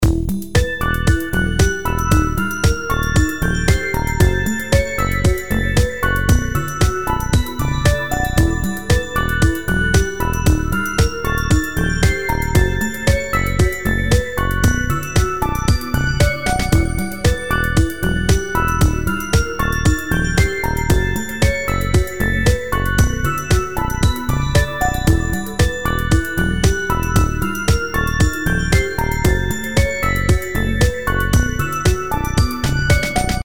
音楽ジャンル： テクノ
LOOP推奨： LOOP推奨
楽曲の曲調： SOFT